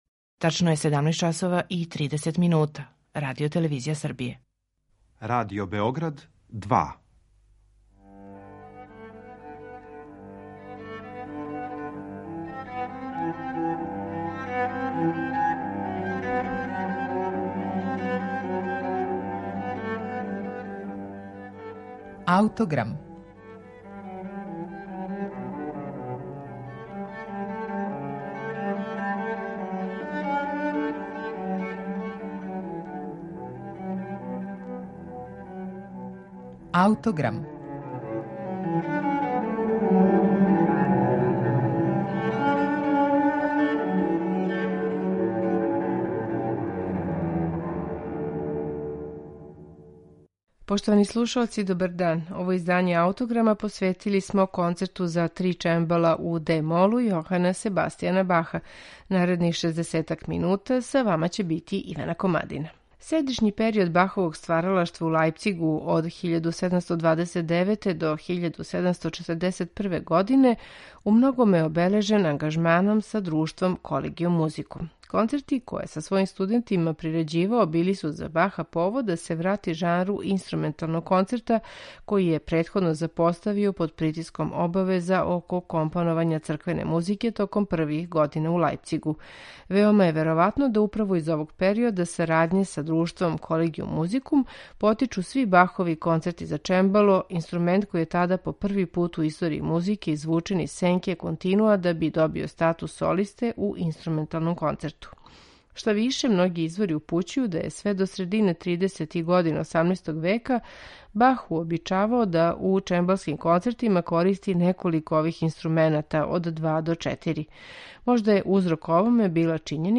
Ј. С. Бах: Концерти за три чембала
Каснија проучавања, међутим, открила су да је овај концерт, као и већина чембалских концерата из лајпцишког периода, настао као прерада концерата за мелодијске инструменте које је Бах раније писао у Кетену. Бахов Концерт за три чембала, којем смо посветили данашњи Аутограм , слушаћете у интерпретацији Кенета Гилберта, Тревора Пинока, Ларса Улрика Мортенсена и ансамбла „The English Concert", под управом Тревора Пинока.